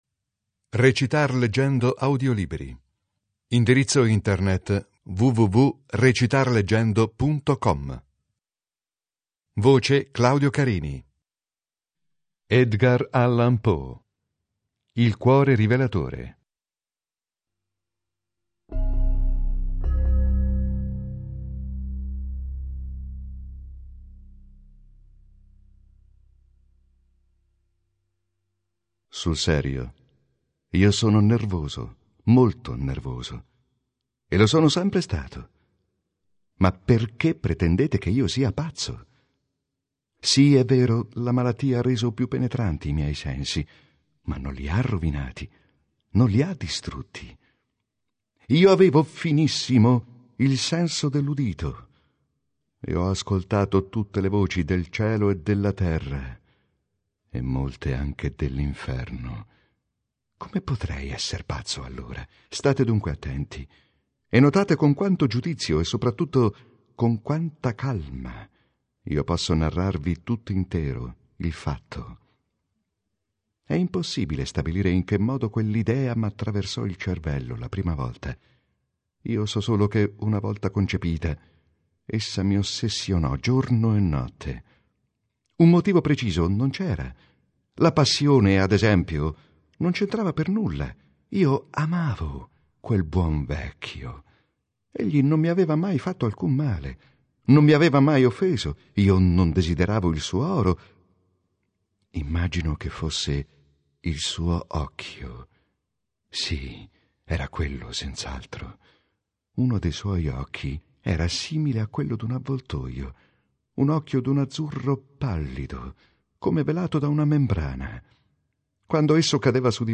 Lettura interpretata
Se vuoi sentir recitare parte di questo brano